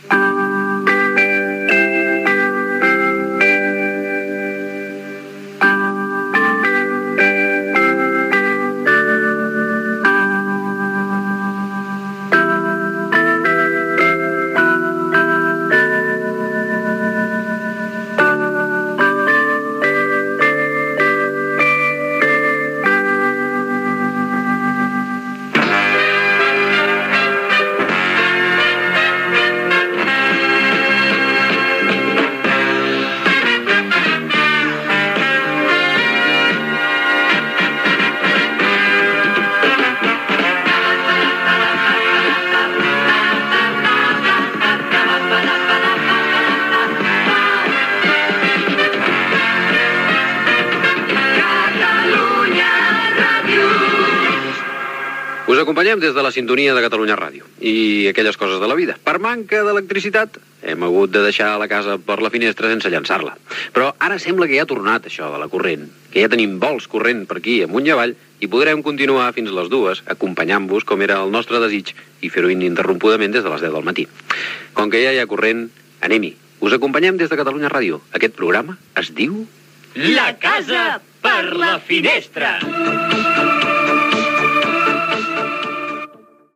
Sintonia, represa de l'emissió després d'un tall en el subministrament elèctric, sintonia, comentari i indicatiu del programa
Entreteniment